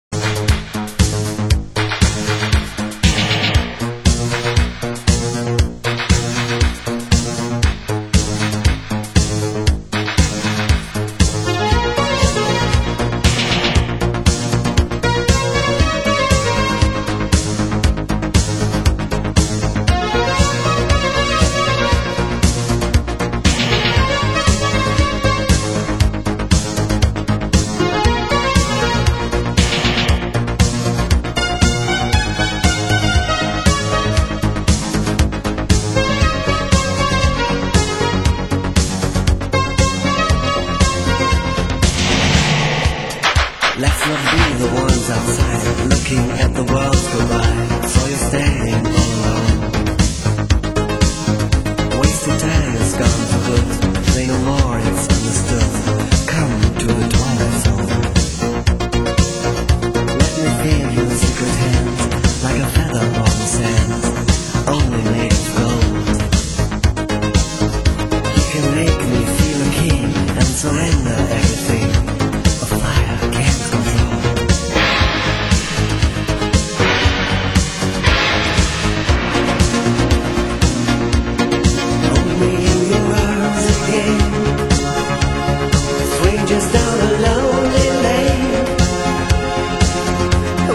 Genre Italo Disco